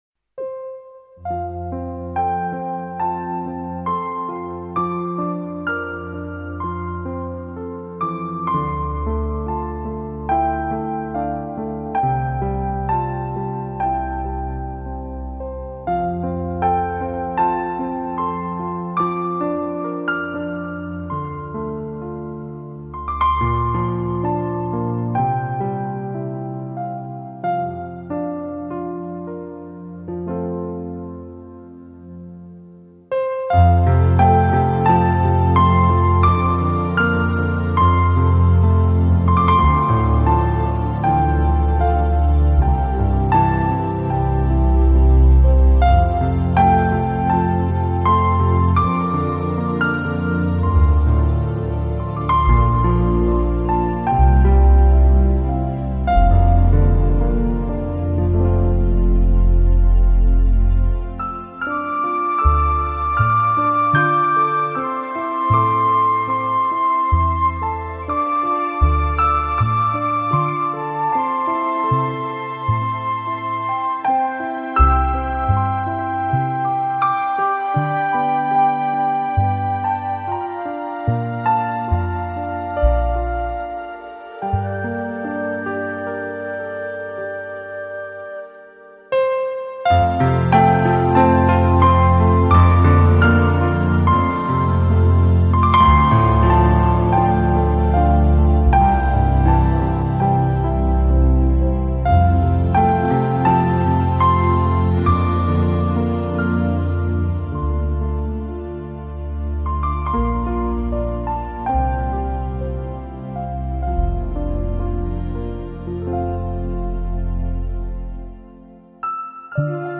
熟悉的旋律重温回忆中的浪漫，悠扬的音符盘旋在寂静的空中。
最出色的十三首作品，首首旋律优美，流畅的吉他、深情的钢琴、感